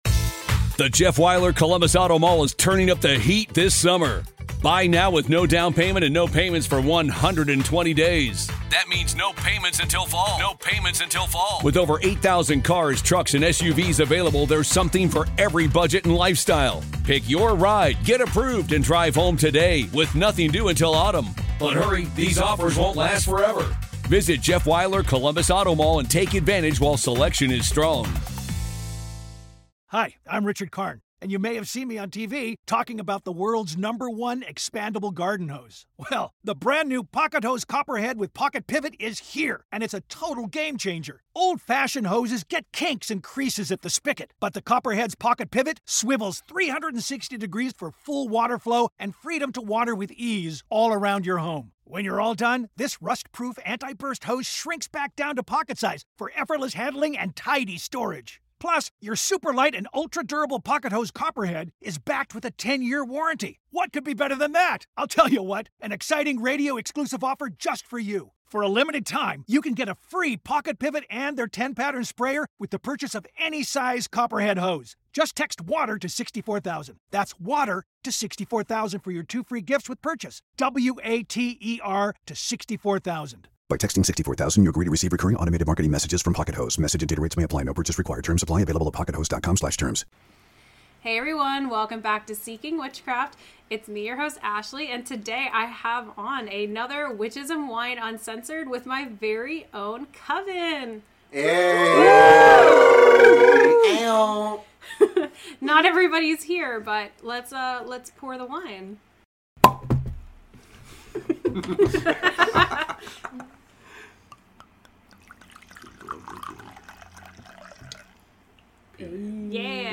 an uncensored discussion about witchcraft while drinking wine.